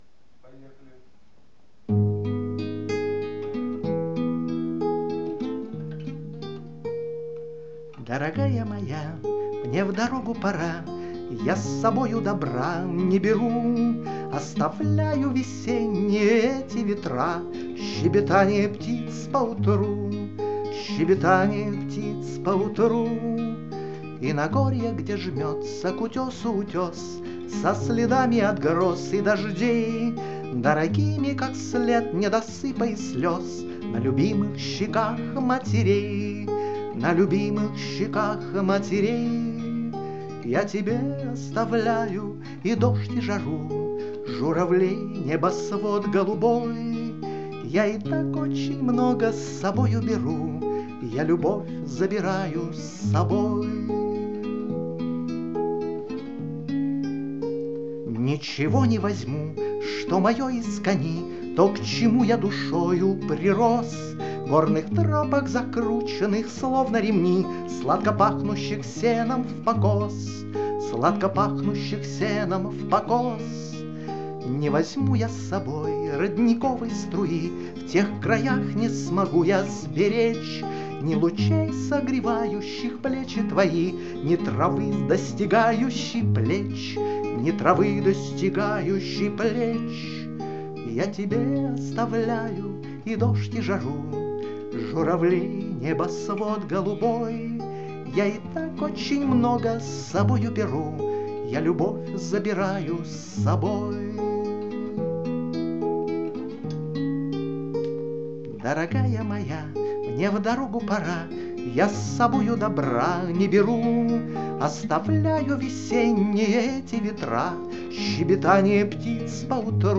Пение, гитара